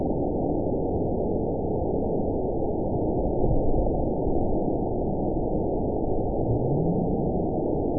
event 919958 date 01/31/24 time 04:02:33 GMT (1 year, 4 months ago) score 9.36 location TSS-AB08 detected by nrw target species NRW annotations +NRW Spectrogram: Frequency (kHz) vs. Time (s) audio not available .wav